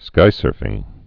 (skīsûrfĭng)